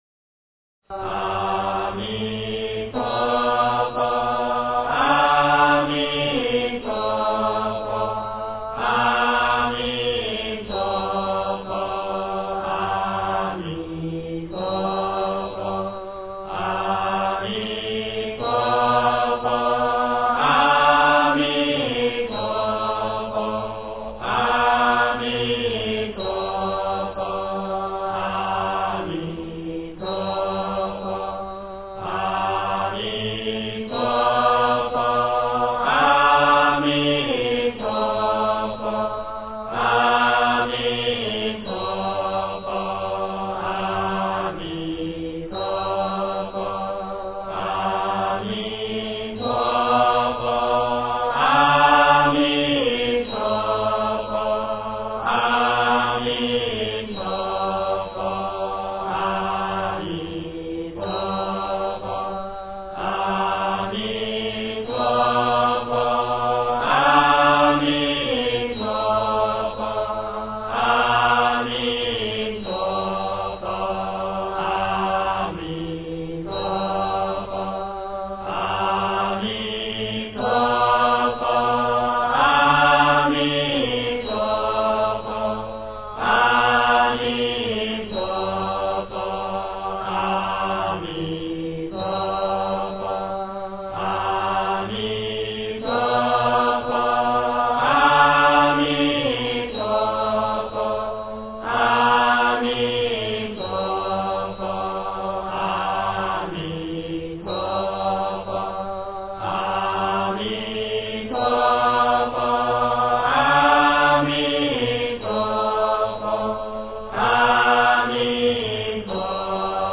四字四音佛号
佛音 经忏 佛教音乐 返回列表 上一篇： 往生咒--僧团 下一篇： 晚课-赞佛偈--僧团 相关文章 金刚般若波罗蜜经--圆光佛学院众法师 金刚般若波罗蜜经--圆光佛学院众法师...